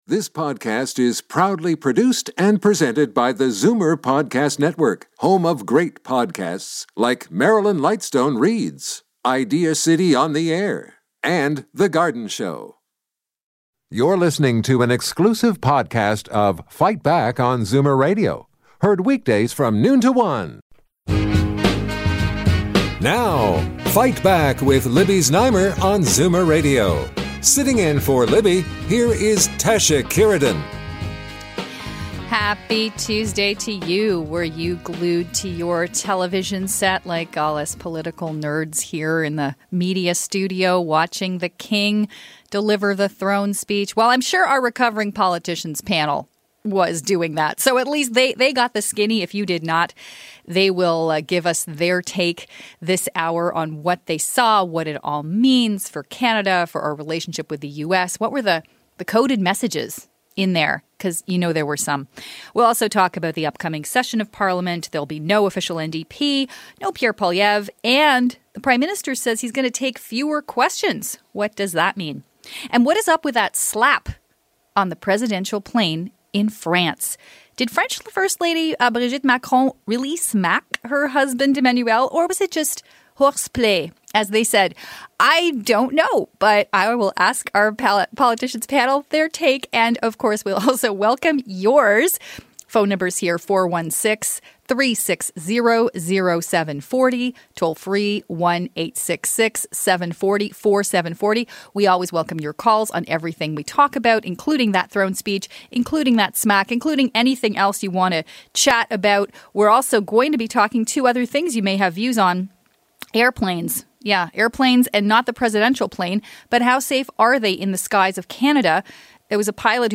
She is joined by George Smitherman, former Liberal MPP for Toronto Centre, Health Minister and Deputy Premier, Cheri DiNovo, former NDP MPP and Lisa Raitt, former Deputy Leader of the Conservative Party of Canada.
Talk News